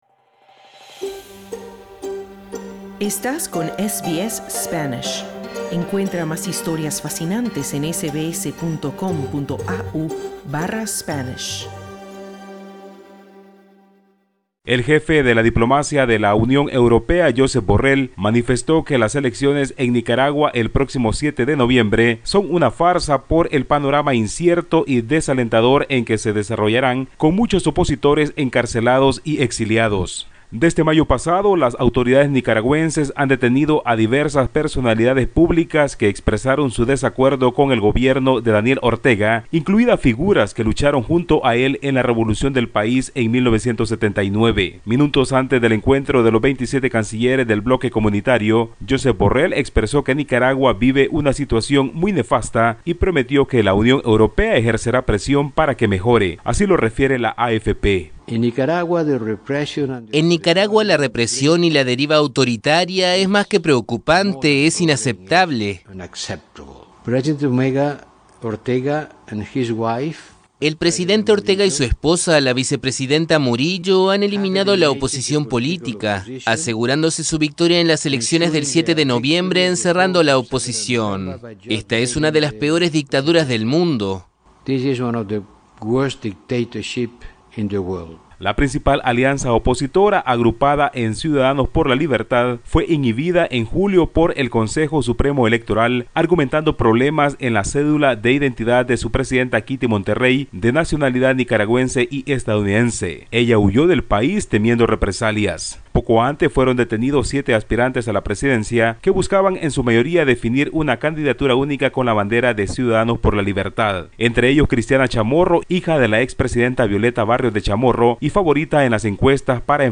Escucha el informe del corresponsal de SBS Spanish en Centroamérica